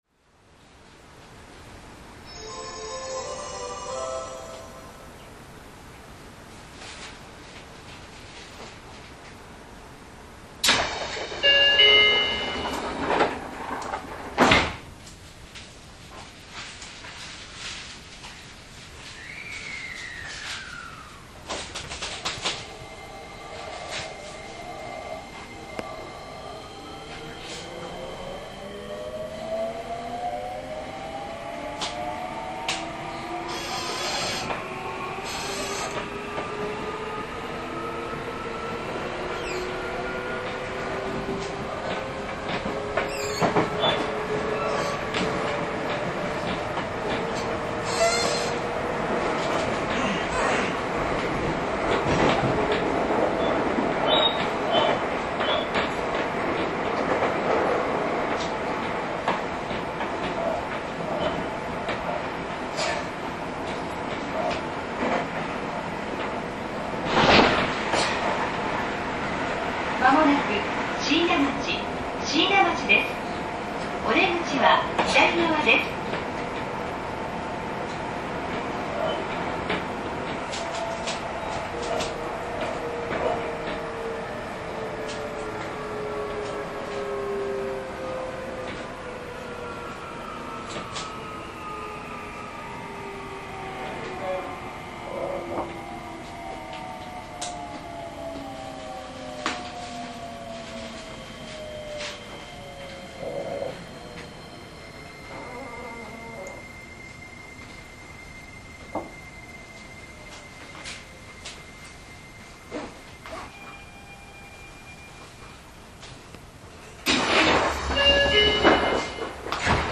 インバーター制御ですが、モーター音が静が過ぎです。
走行音